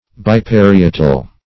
Search Result for " biparietal" : The Collaborative International Dictionary of English v.0.48: Biparietal \Bi`pa*ri"e*tal\, a. [Pref. bi- + parietal.] (Anat.) Of or pertaining to the diameter of the cranium, from one parietal fossa to the other.